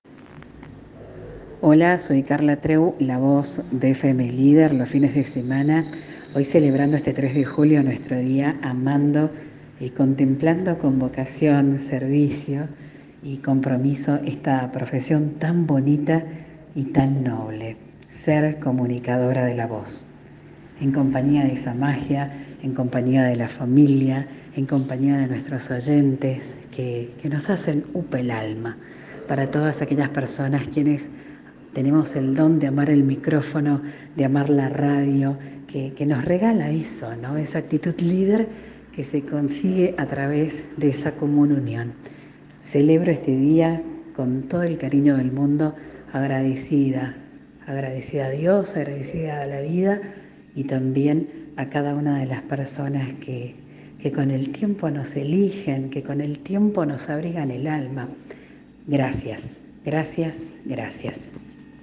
Los fines de semana no serían lo mismo en San Juan sin su voz saliendo de la radio, con tono dulce y sensación de buena compañía.